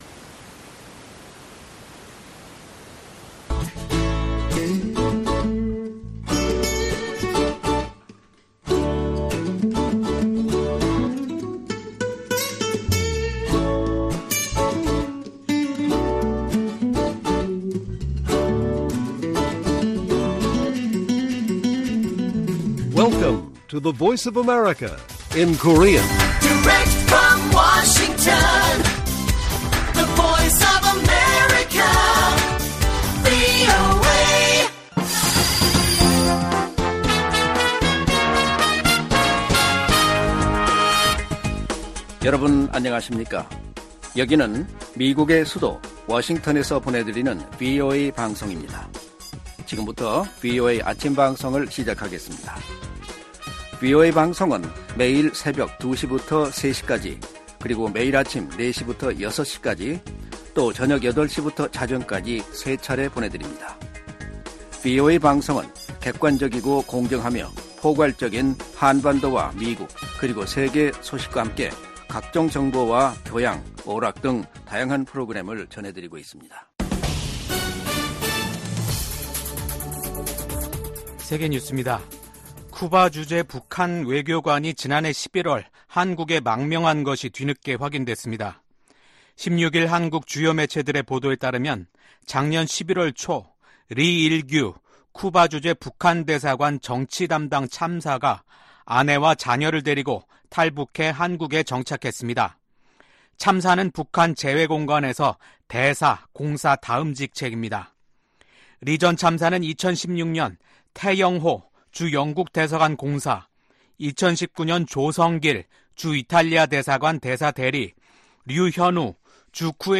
세계 뉴스와 함께 미국의 모든 것을 소개하는 '생방송 여기는 워싱턴입니다', 2024년 7월 17일 아침 방송입니다. '지구촌 오늘'에서는 팔레스타인 무장 조직 하마스와 팔레스타인 자치정부가 중국 중재로 다음주 베이징에서 만나는 소식 전해드리고 '아메리카 나우'에서는 도널드 트럼프 전 대통령이 자신의 러닝메이트, 부통령 후보로 J.D. 밴스 상원의원을 선택했다고 발표한 소식 전해드립니다.